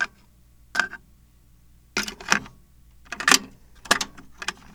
Index of /90_sSampleCDs/E-MU Producer Series Vol. 3 – Hollywood Sound Effects/Miscellaneous/Cassette Door
CASSETTE 01L.wav